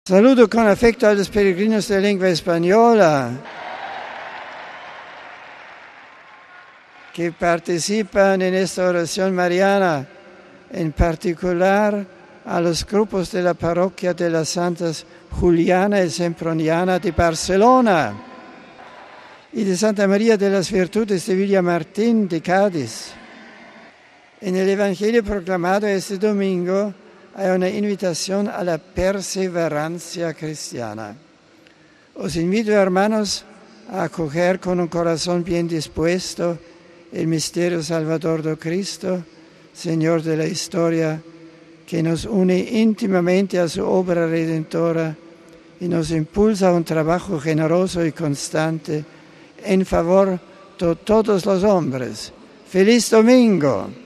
Benedicto XVI como es tradicional ha saludado después en distintas lenguas a los fieles y peregrinos reunidos en la plaza de san Pedro para el rezo del Ángelus.